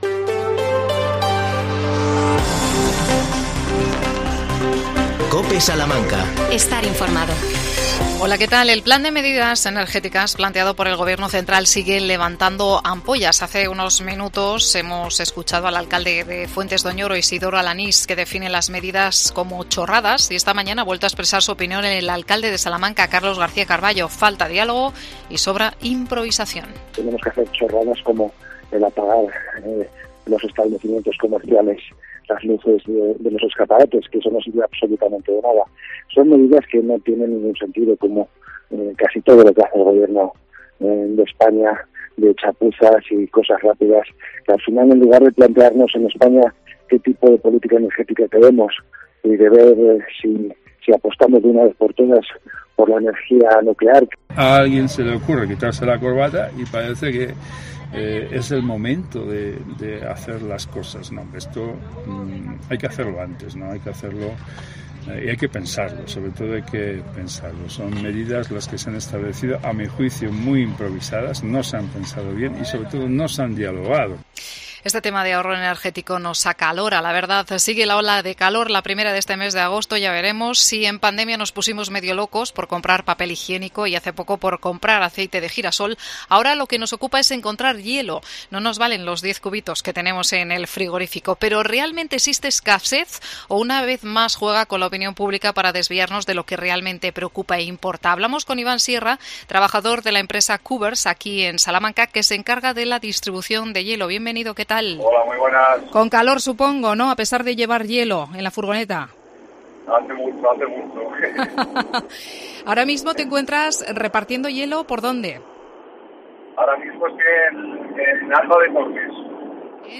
AUDIO: La crisis de los cubitos de hielo llega a Salamanca. El alcalde de Fuentes de Oñoro habla sobre las medidas de ahorro de energía.